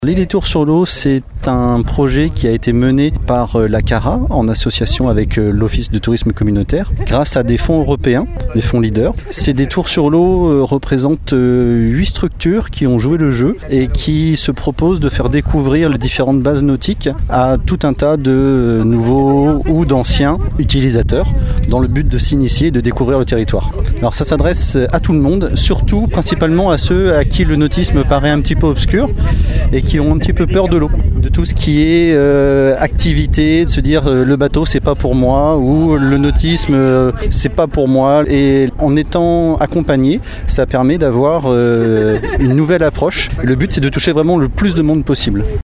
On écoute Vincent Bozier, élu de Meschers-sur-Gironde en charge du nautisme et membre de la commission nautisme à la CARA :
Vincent Bozier présente les Détours sur l’eau.